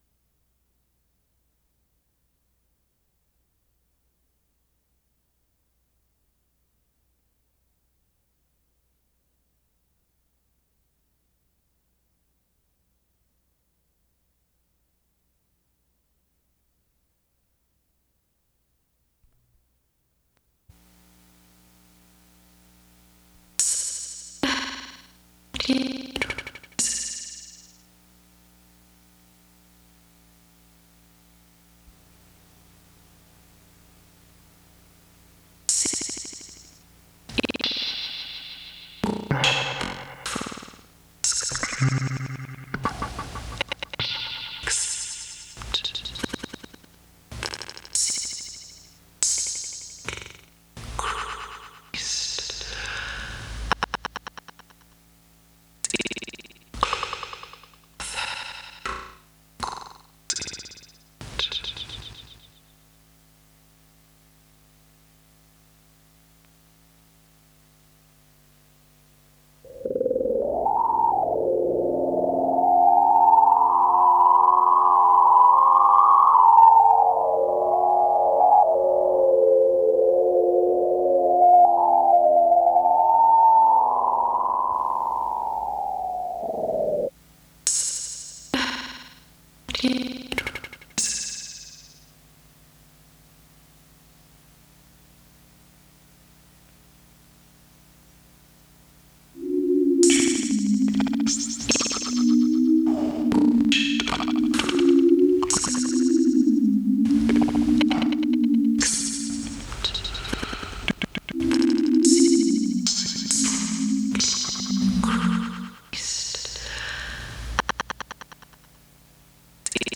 Voicings Lecture
MIT Experimental Music Studio recordings